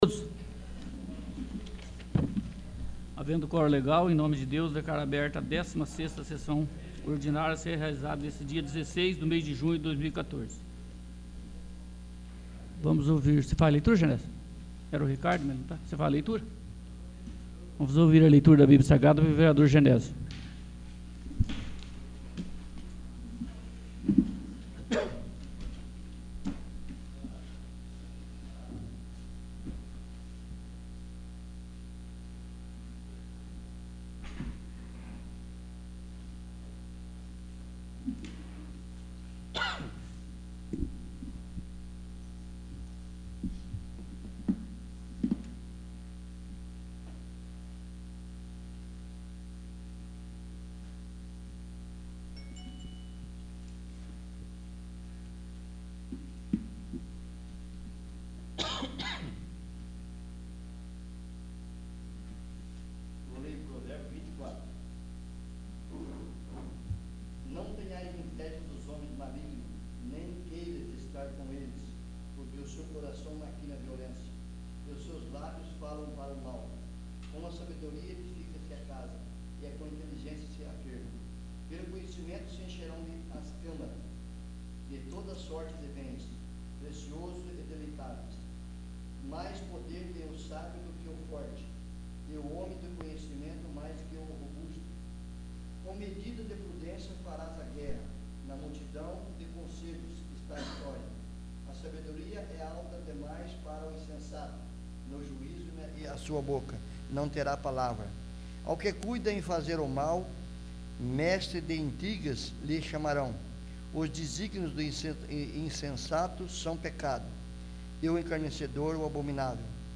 16º. Sessão Ordinária